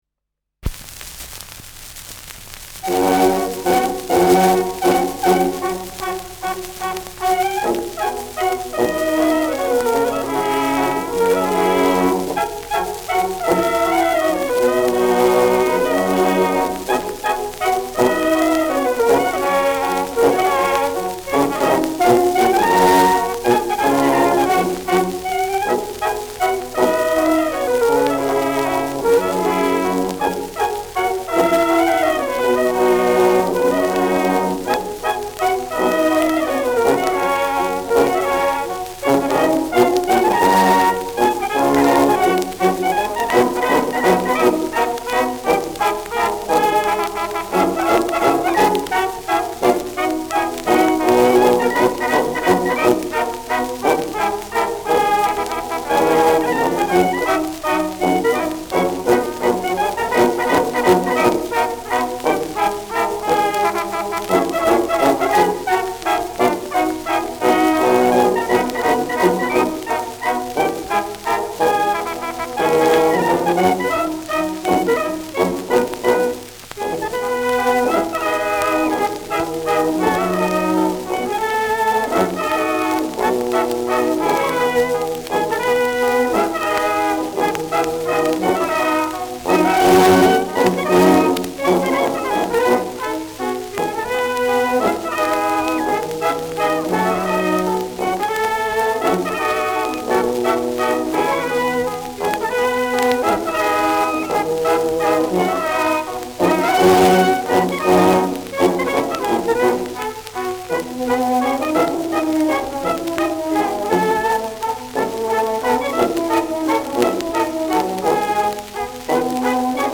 Schellackplatte
präsentes Rauschen : präsentes Knistern : leiert : abgespielt
Maxglaner Bauernkapelle, Salzburg (Interpretation)